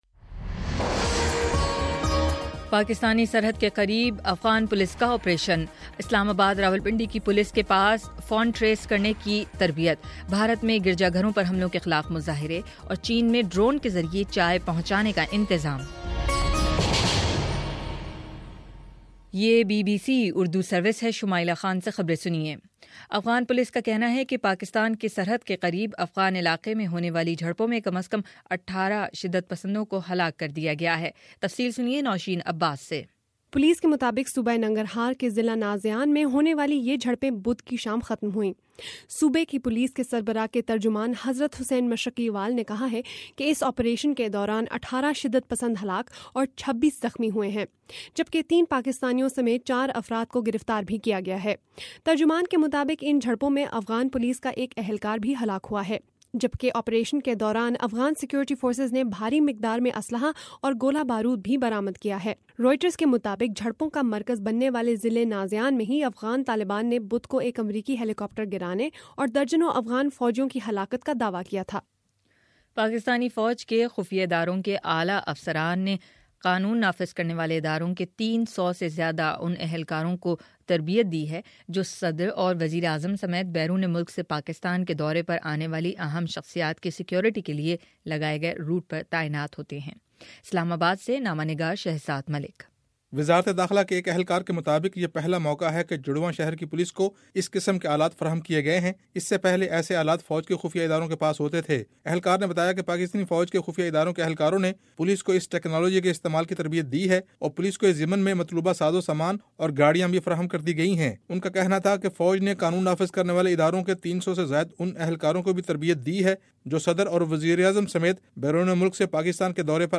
فروری05 : شام سات بجے کا نیوز بُلیٹن